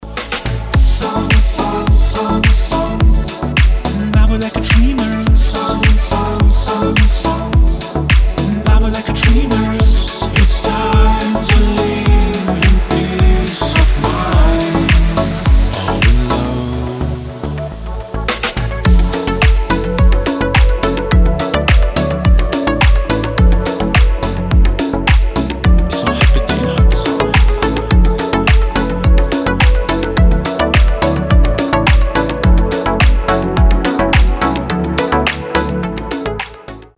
• Качество: 320, Stereo
deep house
dance
приятные
летние
теплые